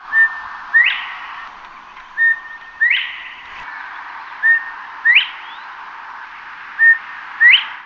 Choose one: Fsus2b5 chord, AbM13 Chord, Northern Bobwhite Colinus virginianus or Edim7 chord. Northern Bobwhite Colinus virginianus